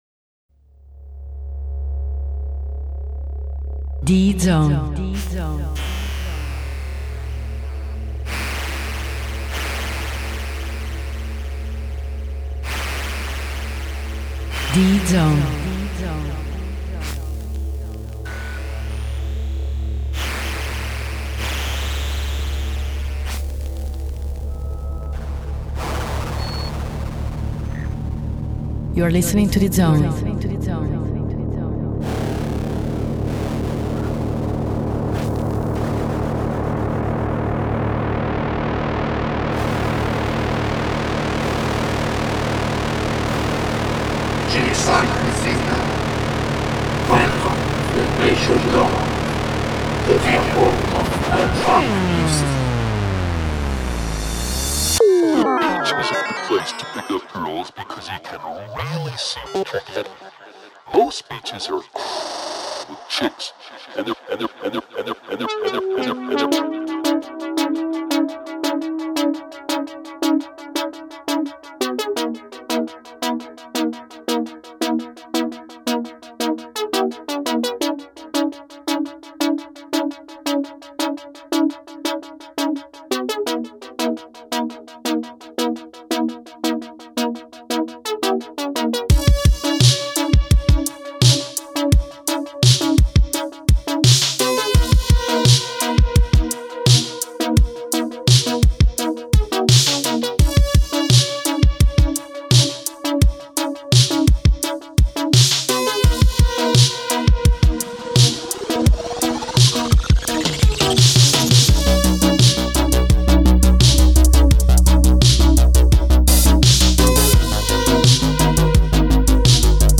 For all the Electronic/Electro music lovers!
60-minute electronic atmospheres